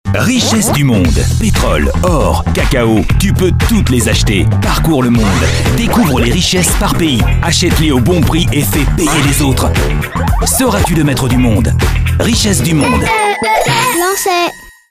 Un timbre de voix allant du médium au grave, Une voix élégante et chaleureuse, avec une large palette de styles d'interprétation et de tons.
Voix off impliquée et un ton promo pour ce spot TV réalisé par Lansay, créateur de jeux pour enfants.